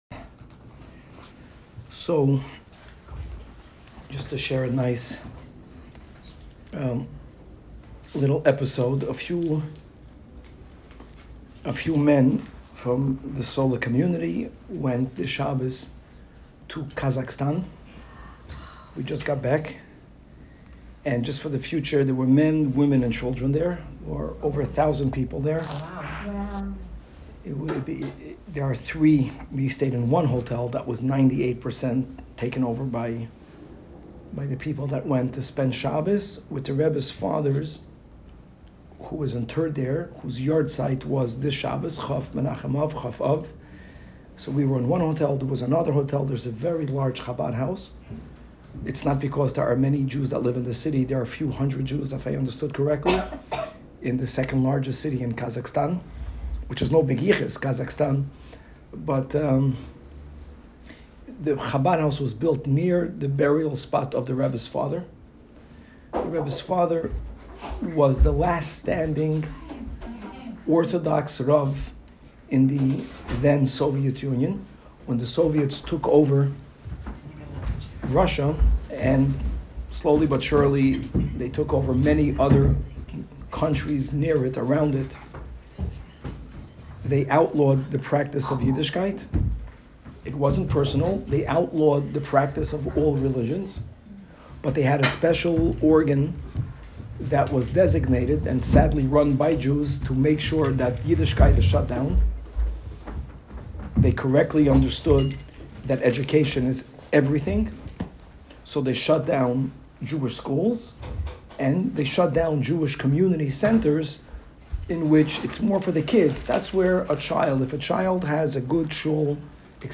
Woman's Class